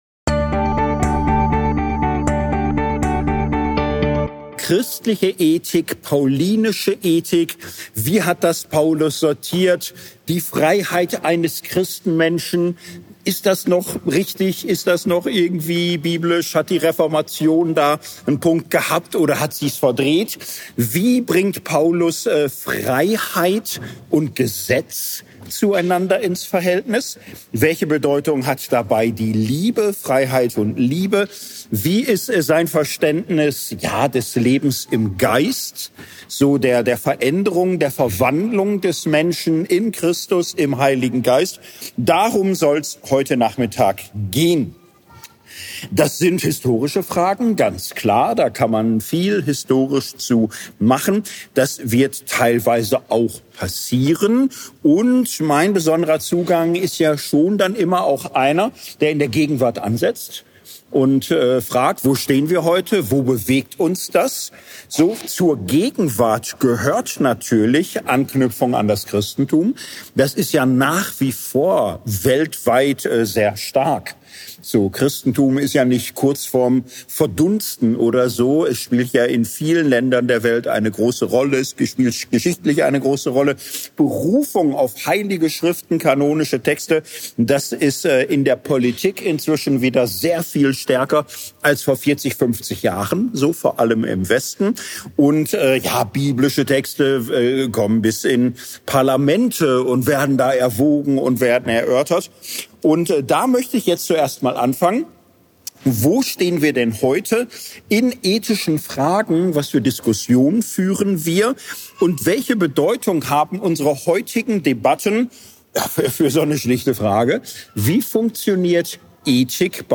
Hier lohnt es sich, zuerst den Vortrag über Erasmus von…